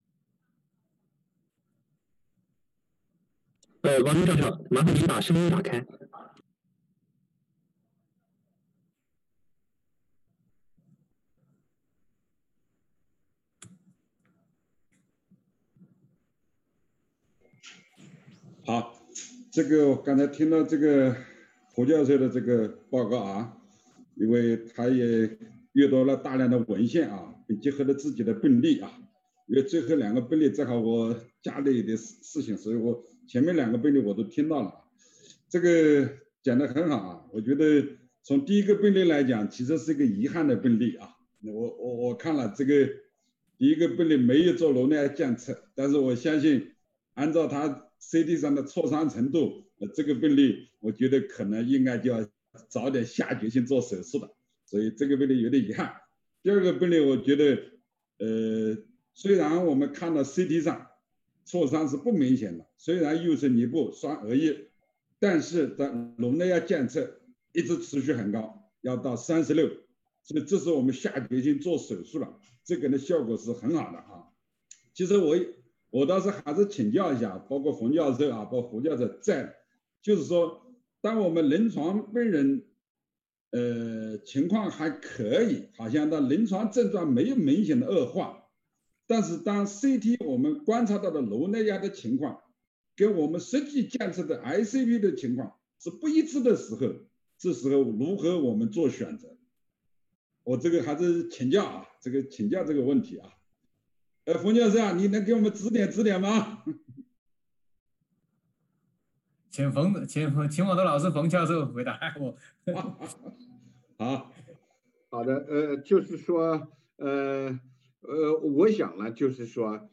精彩点评